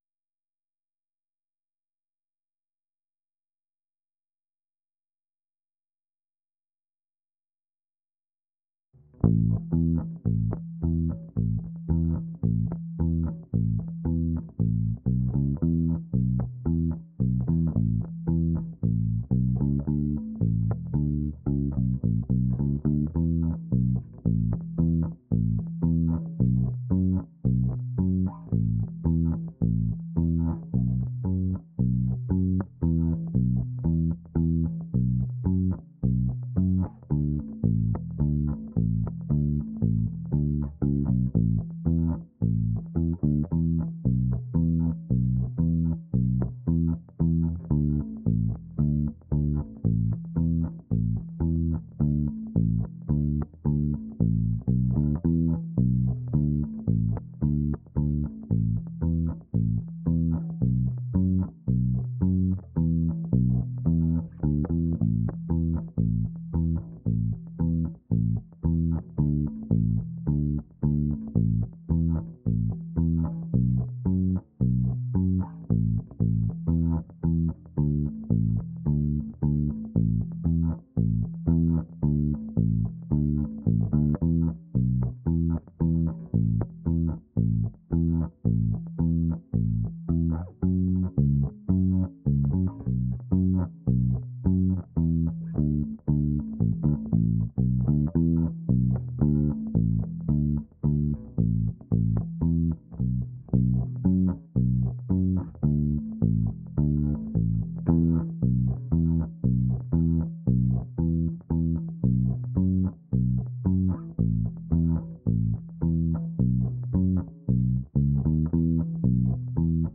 bassguitar.wav